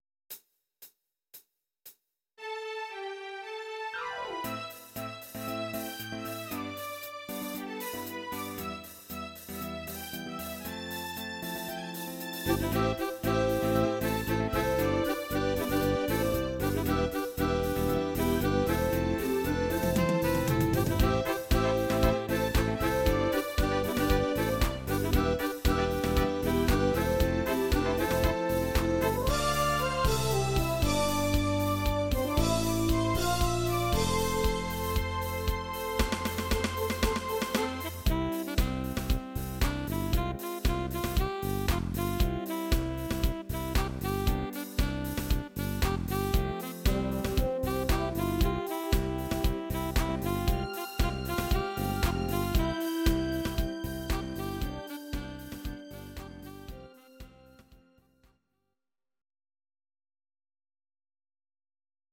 Audio Recordings based on Midi-files
Pop, Musical/Film/TV, 1970s